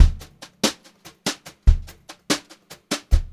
144GVBEAT2-L.wav